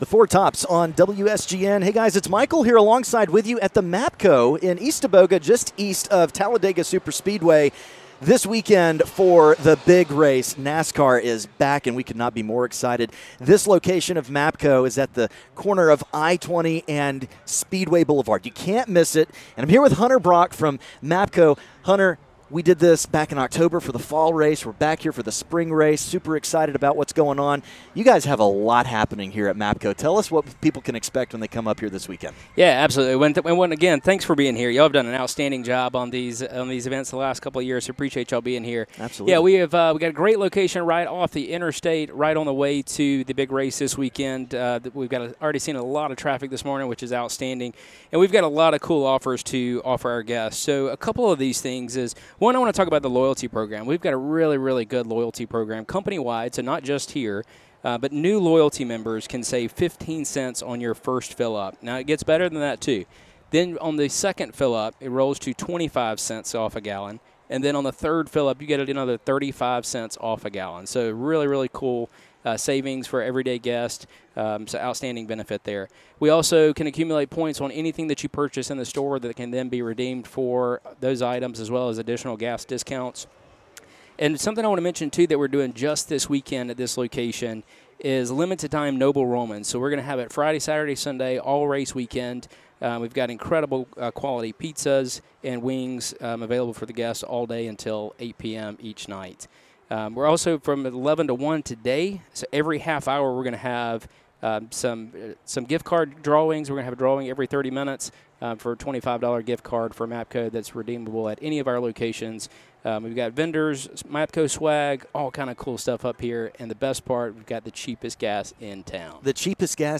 Live from the MAPCO on Speedway Blvd.